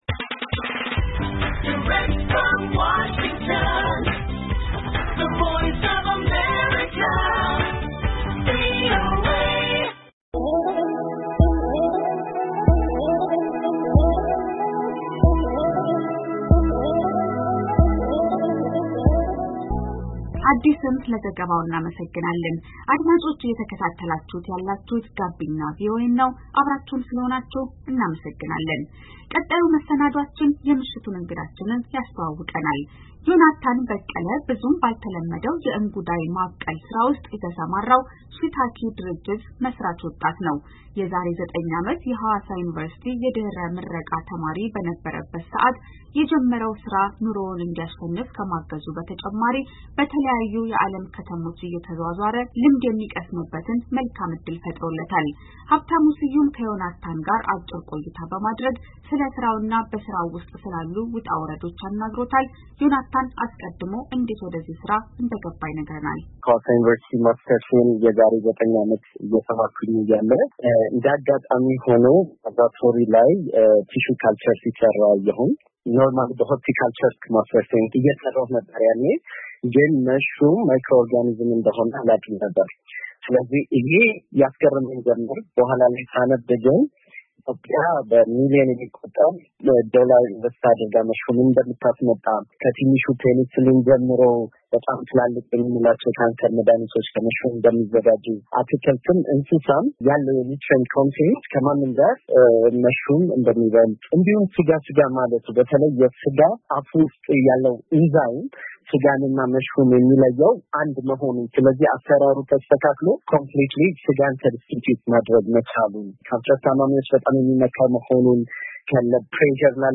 እንጉዳይ እንደ ስጋ ፦ ቆይታ ከእንጉዳይ አምራቹ ወጣት ጋር